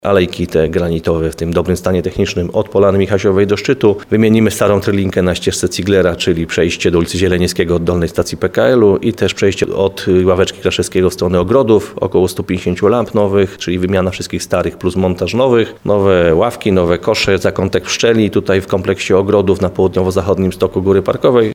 Jak mówi burmistrz Krynicy-Zdroju Piotr Ryba, prace w większości obejmą szlak, który biegnie stokami Góry Parkowej, aby trasa mogła być bardziej przyjazna dla osób starszych, czy matek z dziećmi.